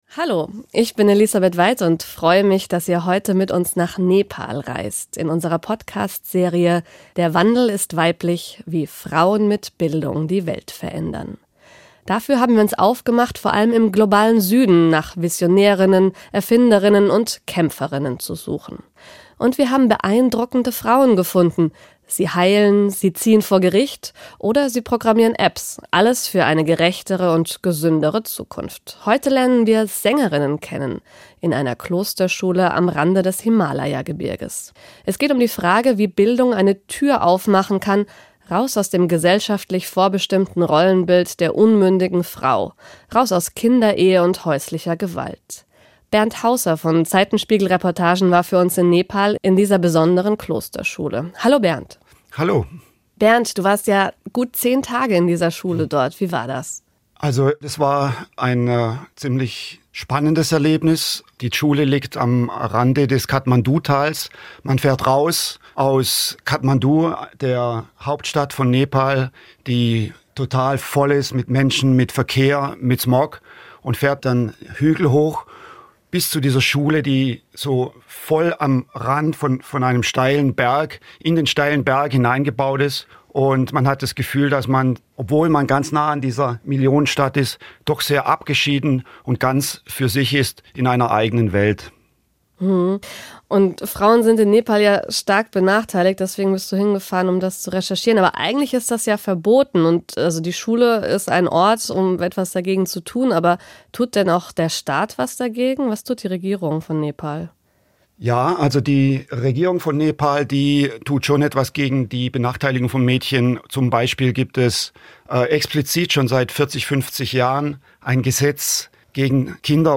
10-teilige Radio- und Podcast-Serie